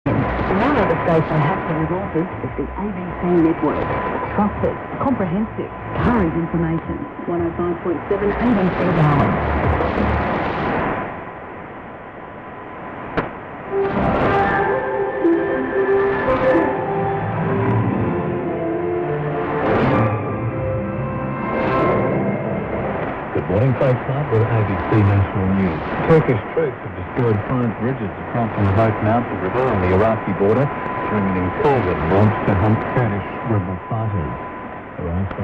１年中を通して、夜間結構安定して聞こえるのがABC(オーストラリアのNHKのようなもの）の中短波放送です。
３つの周波数のどれも、ちょっと遠くの日本の民放よりもきれいに受信できたりしますが、中でも一番強いのが2485kHzのKatherineからの放送です。